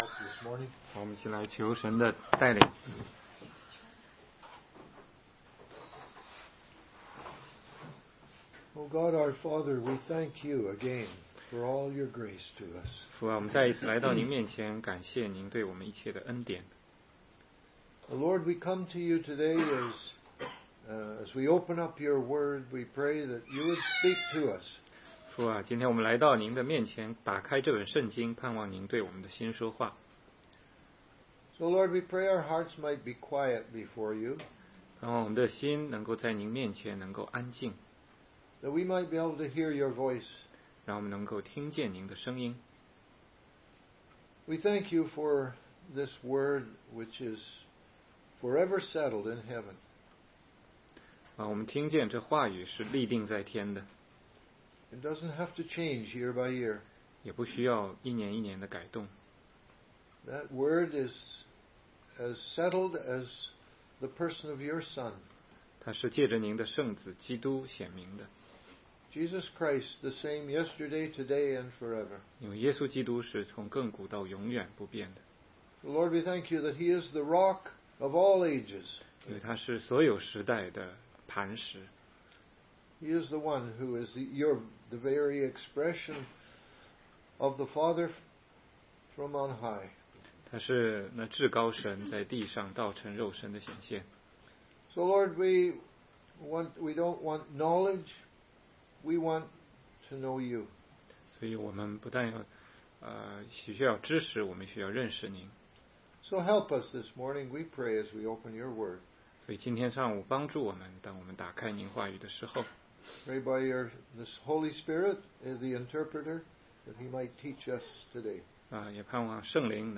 16街讲道录音 - 约翰福音10章22-26节：怎么知道耶稣就是弥赛亚